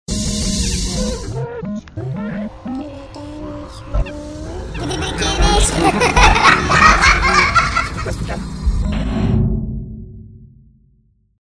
JINGLES